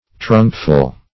Search Result for " trunkful" : The Collaborative International Dictionary of English v.0.48: Trunkful \Trunk"ful\, n.; pl. Trunkfuls . As much as a trunk will hold; enough to fill a trunk.
trunkful.mp3